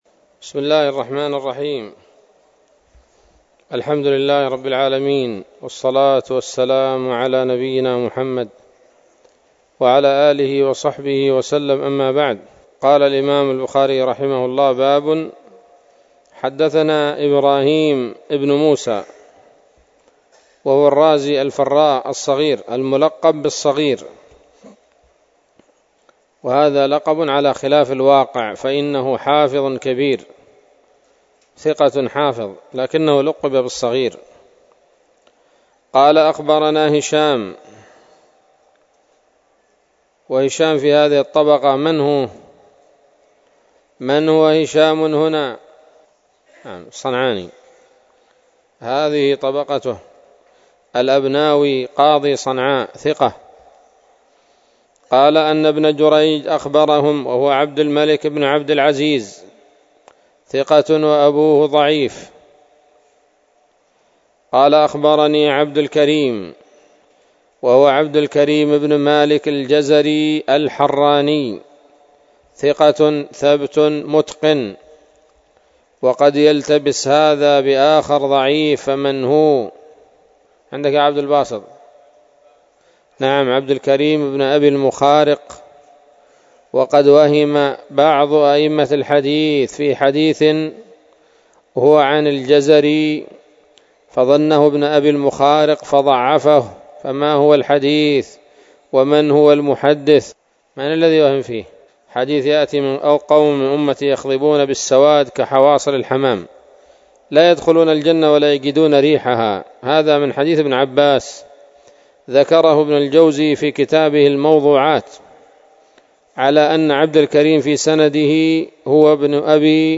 الدرس الخامس من كتاب المغازي من صحيح الإمام البخاري